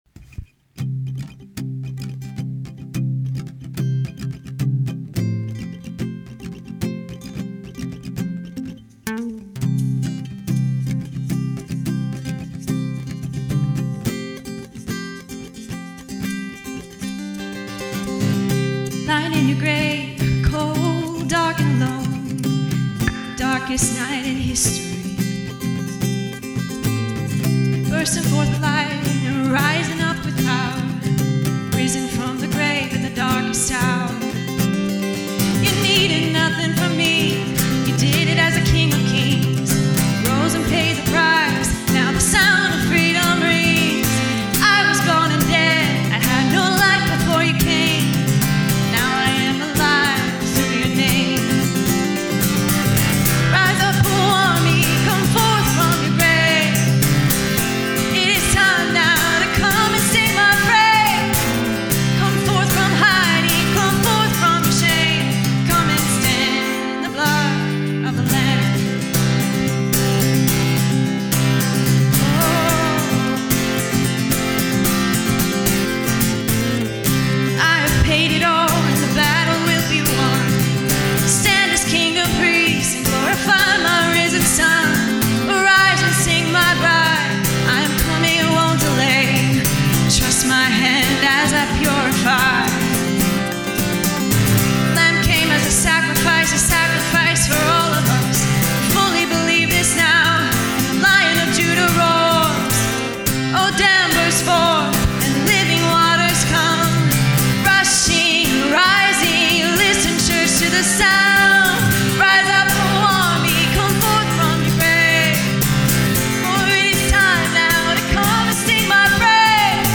Category: Special